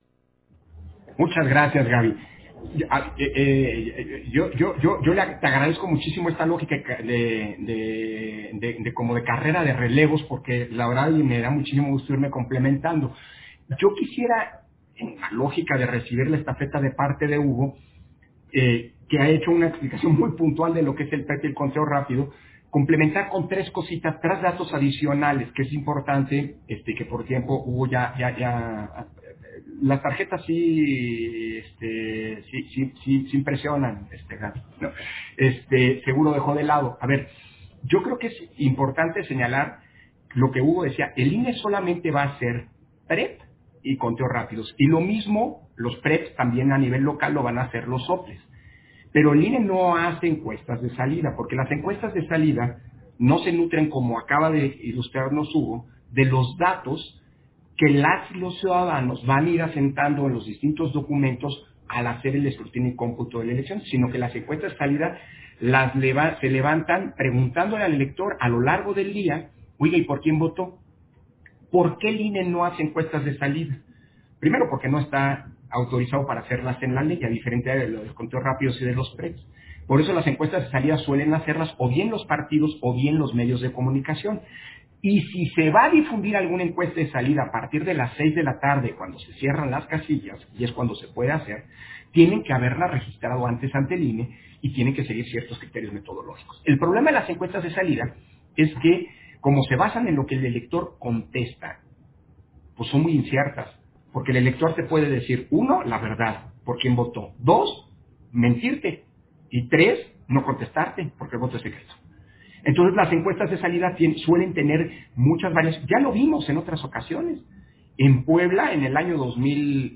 Intervenciones de Lorenzo Córdova, en la Mesa Redonda: Organización y Retos de la Elección